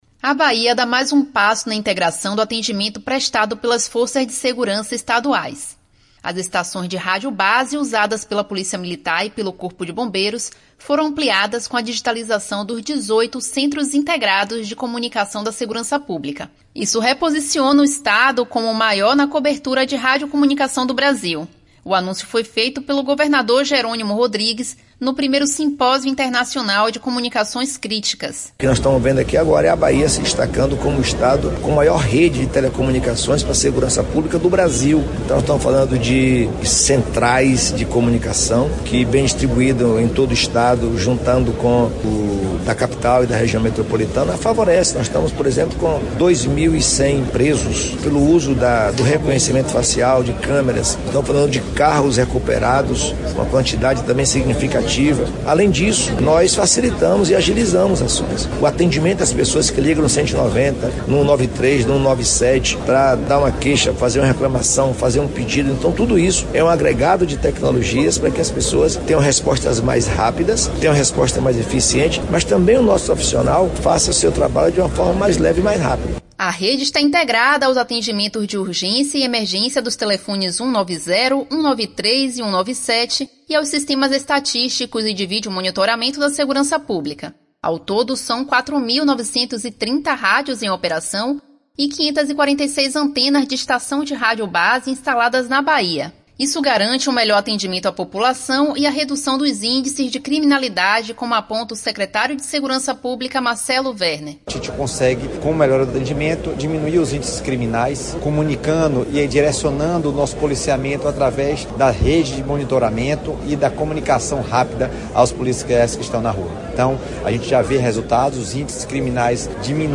A ampliação dos serviços de registro de ocorrências de urgência e emergência, através do 190, 193 e 197, foi anunciada na tarde desta terça-feira (26), pelo Governo do Estado, por meio da Secretaria da Segurança Pública (SSP), durante o ‘I Simpósio Internacional de Comunicações Críticas’, no Cimatec, em Salvador.
🎙Jerônimo Rodrigues – Governador Bahia